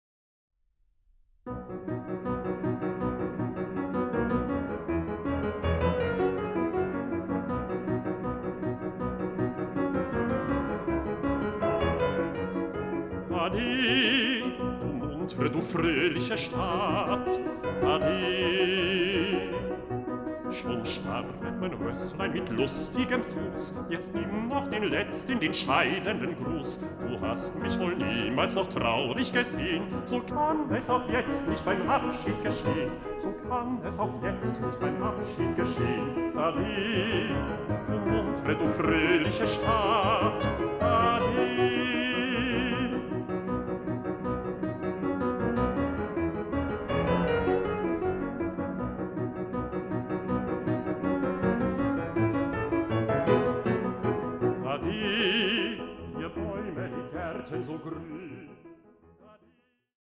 Bassbariton
Klavier
Aufnahme: Ölbergkirche Berlin, 2024